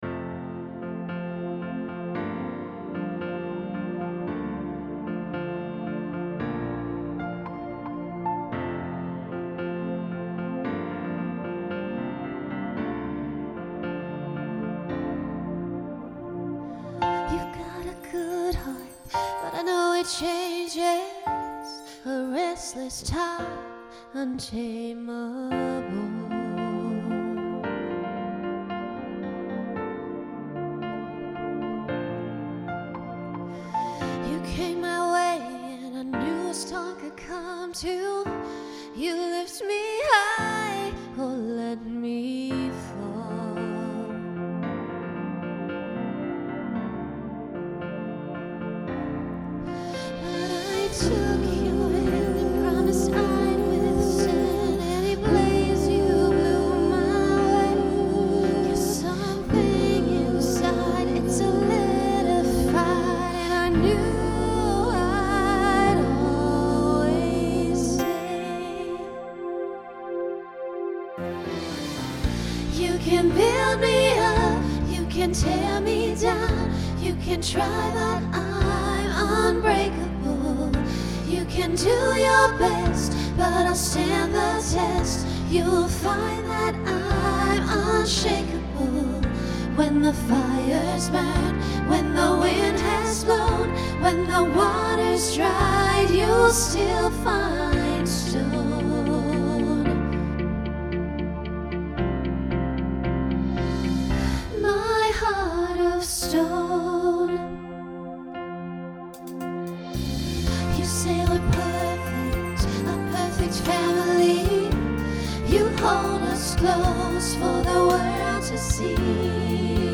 New SATB voicing for 2022.
Genre Broadway/Film
Function Ballad